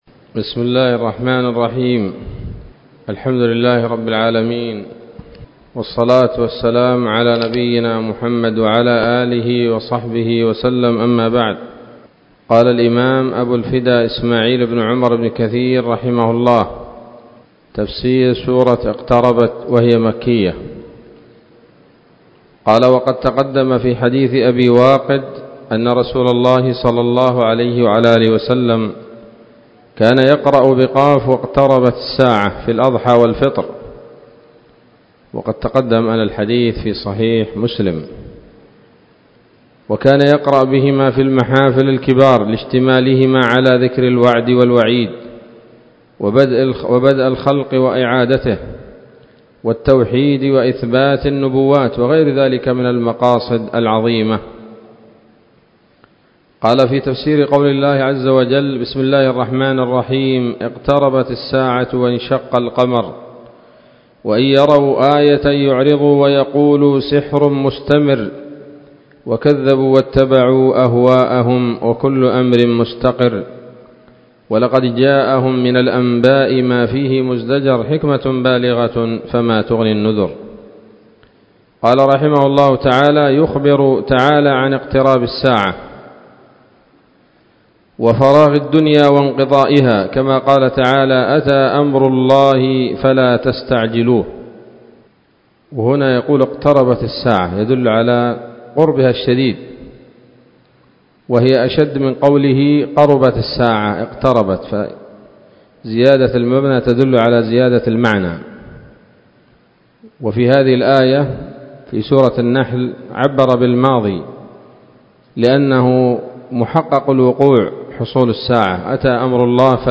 الدرس الأول من سورة القمر من تفسير ابن كثير رحمه الله تعالى